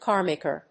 /ˈkɑˌrmekɝ(米国英語), ˈkɑ:ˌrmeɪkɜ:(英国英語)/
アクセント・音節cár・màker